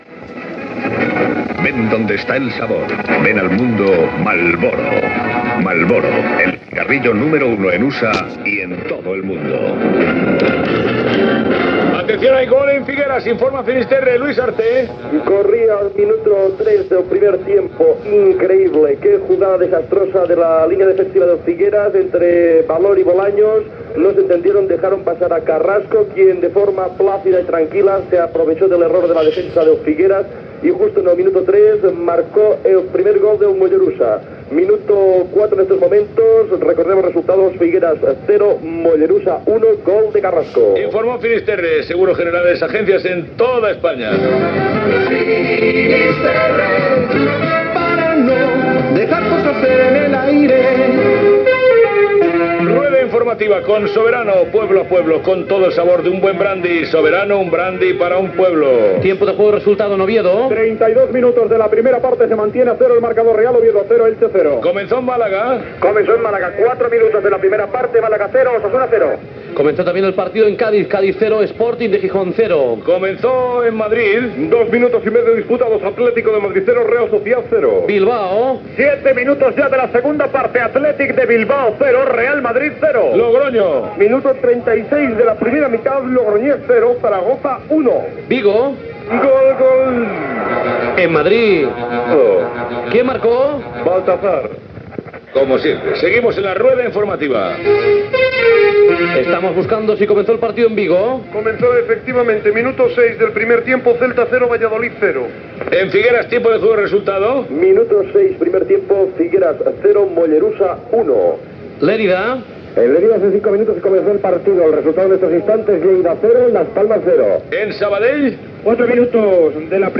Publicitat, gol al partit Figueres-Mollerussa, roda informativa de partits de futbol,.
Esportiu